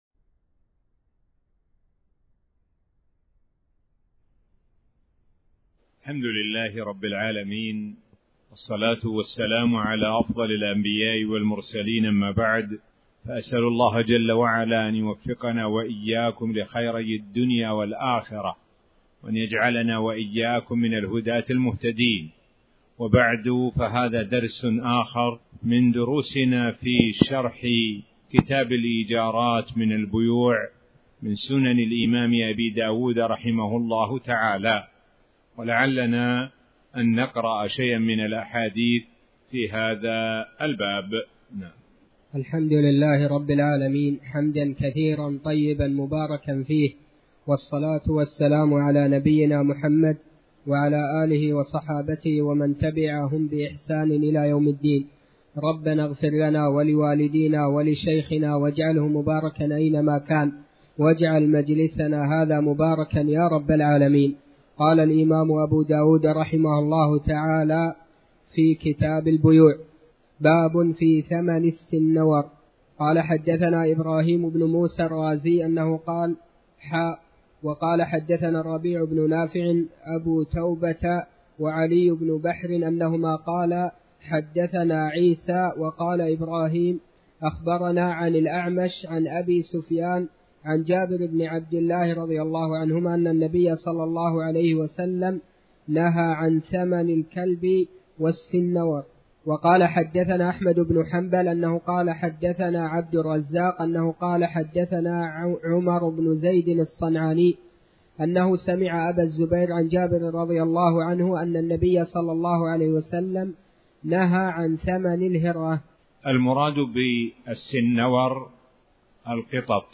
تاريخ النشر ٢٥ ذو الحجة ١٤٣٩ هـ المكان: المسجد الحرام الشيخ: معالي الشيخ د. سعد بن ناصر الشثري معالي الشيخ د. سعد بن ناصر الشثري باب في ثمن السنور